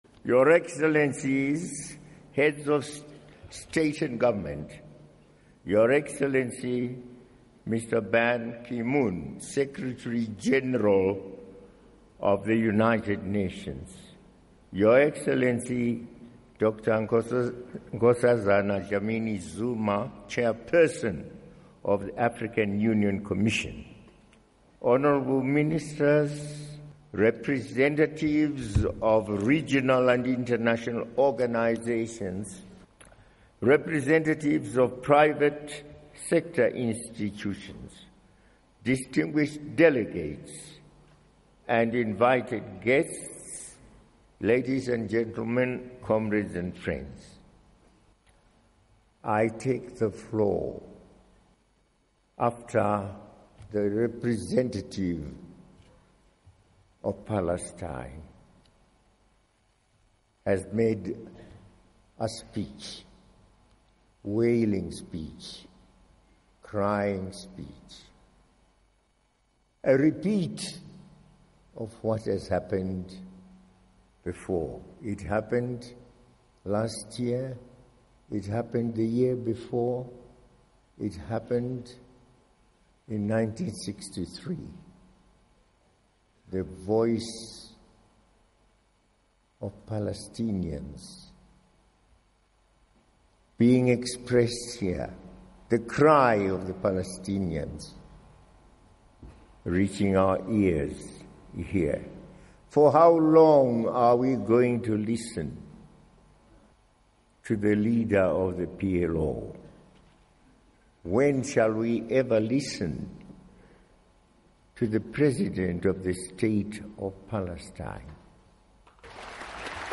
President Robert Mugabe Of Zimbabwe Addresses 26th AU Summit - Full Speech